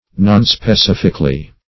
nonspecifically - definition of nonspecifically - synonyms, pronunciation, spelling from Free Dictionary